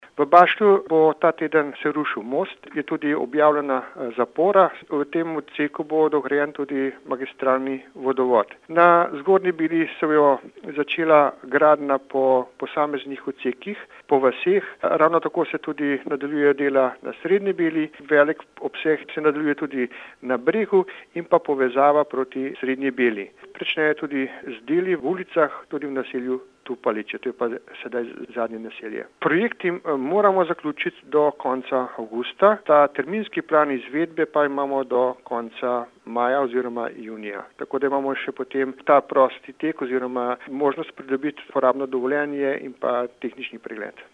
35649_izjavazupanamiranazadnikarjaoinfrastrukturi.mp3